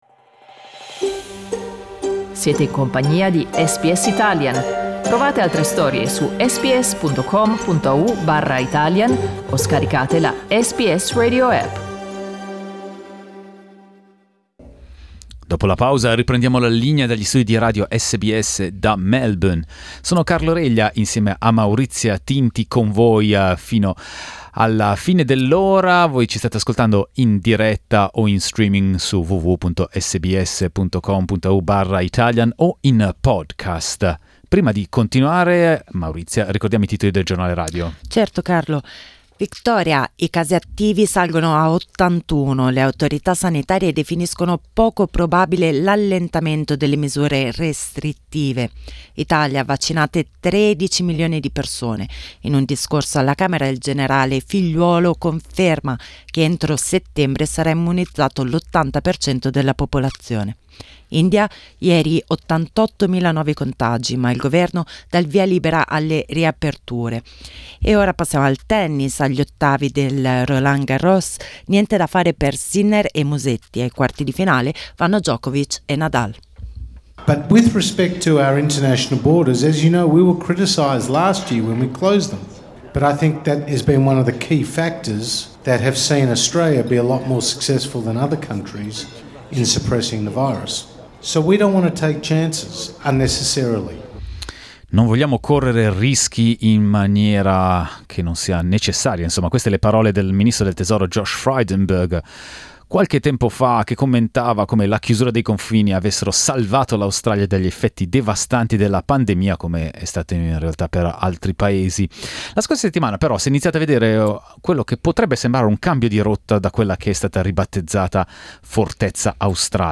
Gli ascoltatori di SBS Italian hanno raccontato cosa pensano del possibile progetto pilota del governo federale, che prevederebbe la possibilità di partire se vaccinati.